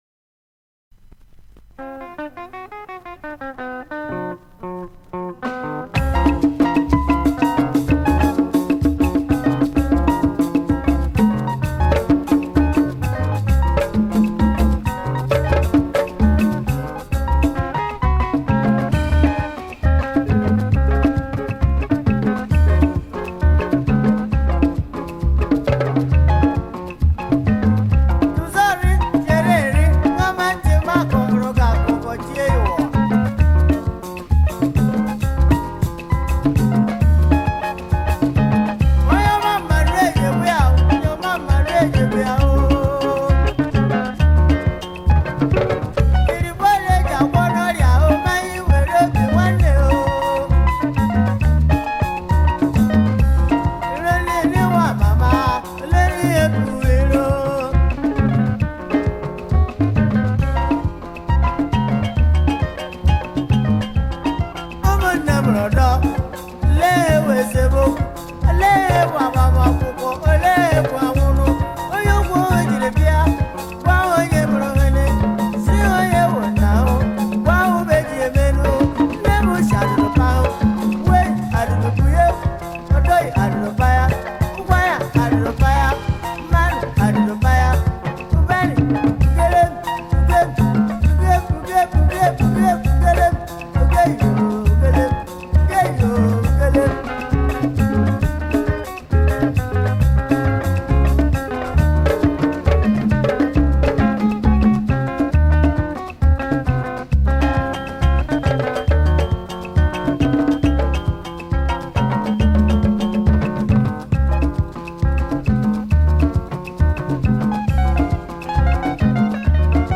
Multi-talented Nigerian Highlife duo